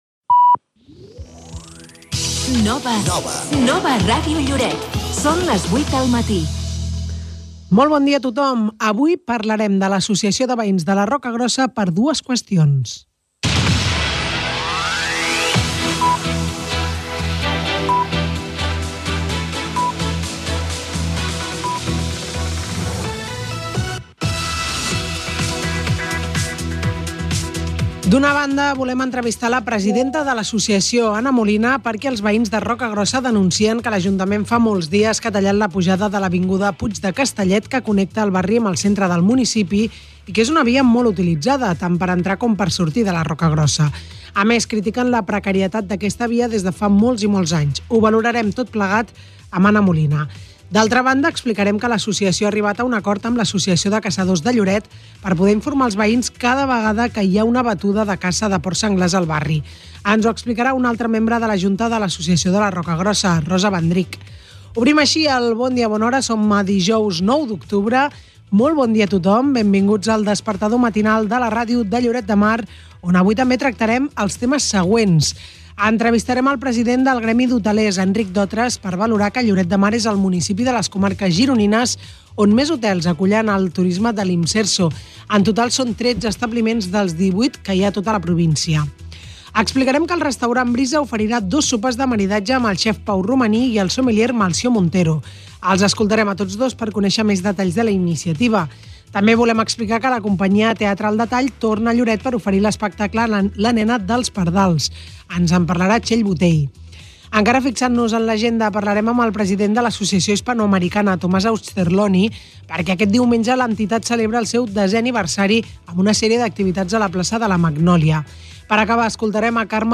Indicatiu de la ràdio, hora, tema principal del programa, sumari de continguts informatius, indicatiu del programa
Info-entreteniment